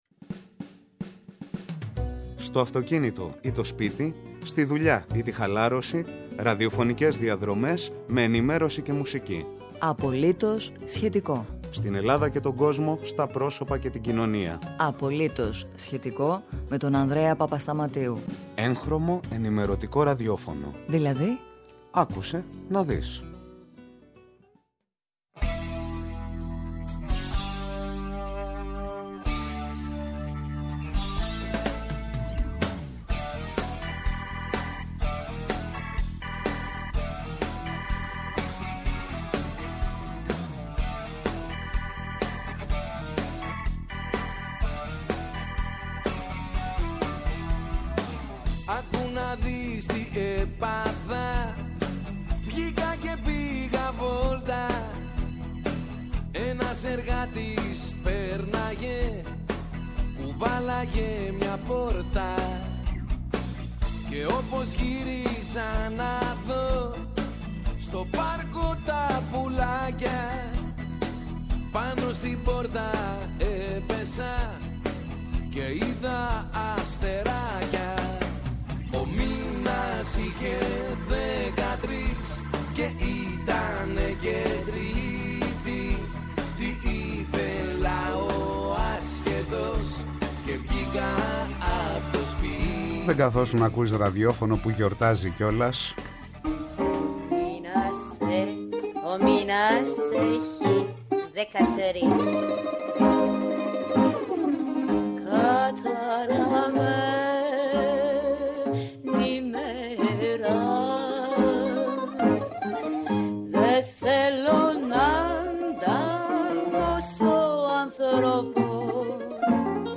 Μια κουβέντα για την ίδρυση και την πορεία της Κυπριακής Ραδιοφωνίας, την σχέση του ίδιου με το Ραδιόφωνο, το παρόν και το μέλλον της Ραδιοφωνίας.